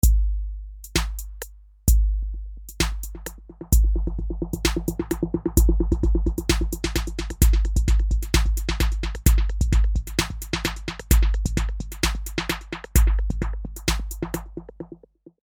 Great features - including clean delay lines with independent control on left and right channels, tempo reference, eq and more - will make this your faithful sidekick for frequent musical applications.
Each sequence is in a dry version and then effected in different ways.
Sync Delay.mp3